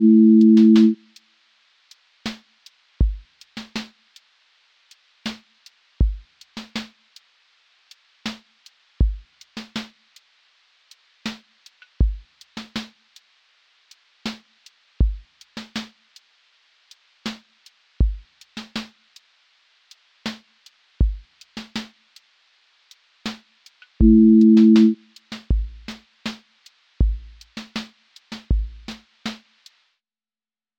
lofi crackle sway
QA Listening Test lofi Template: lofi_crackle_sway
• voice_kick_808
• voice_snare_boom_bap
• voice_hat_rimshot
• texture_vinyl_hiss
• tone_warm_body
• fx_space_haze_light
• voice_sub_pulse